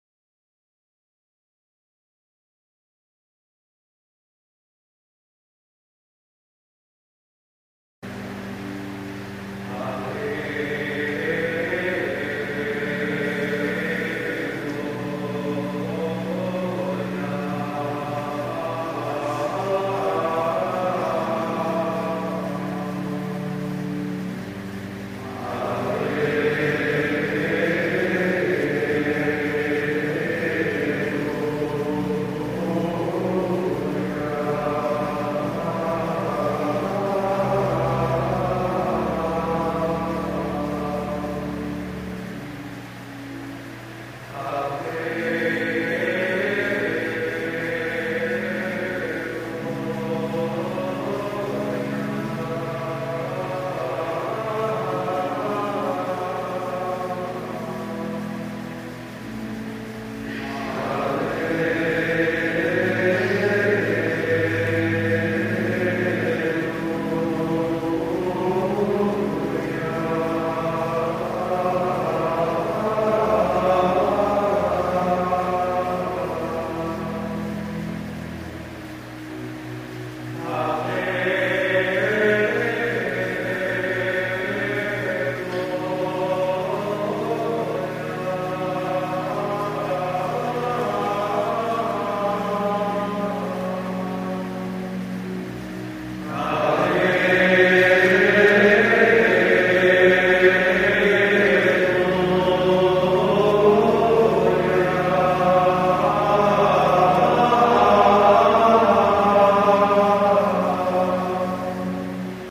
Chủ tế hát 3 lần, mỗi lần lên một cung
Cộng đoàn đáp lại sau mỗi lần chủ tế xướng
Easter Vigil Alleluia - Gregorian Chant (Mode VIII)
Gregorian Chant là lối hát bình ca có từ thời Giáo Hoàng Gregory I